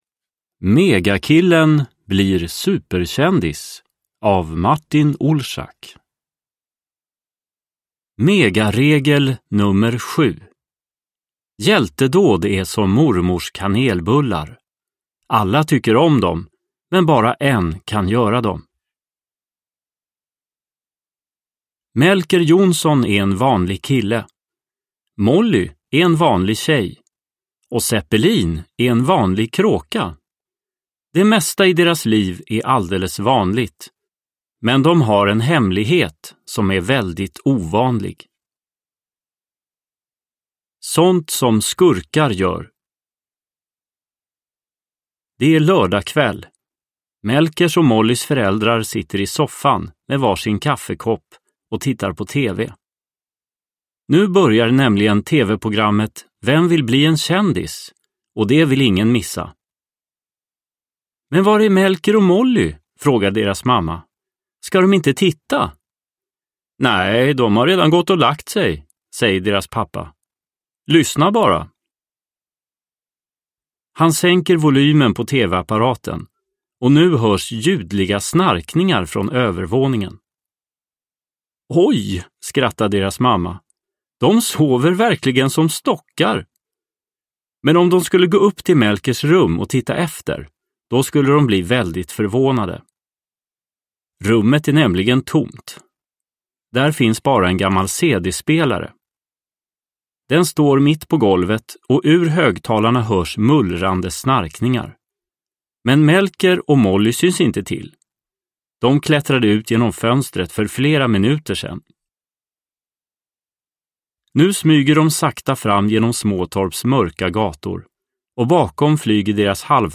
Megakillen blir superkändis – Ljudbok – Laddas ner